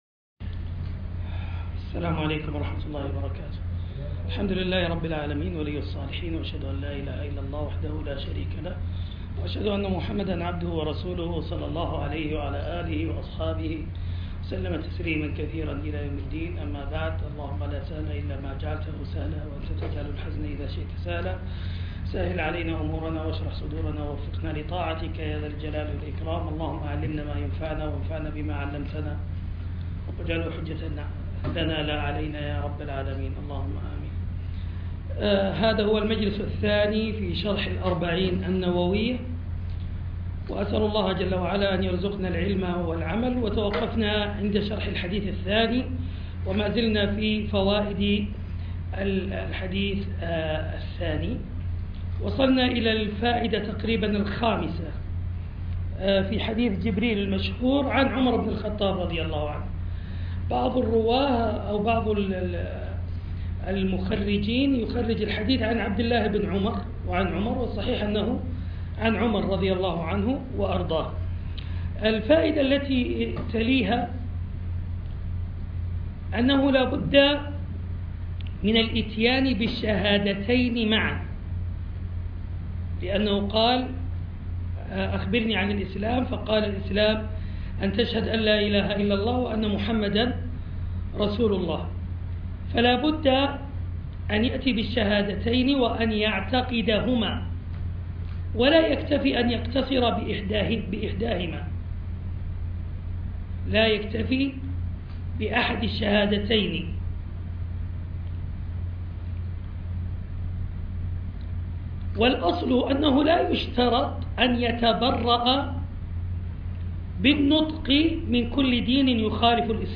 الحديث وعلومه     شرح كتب حديثية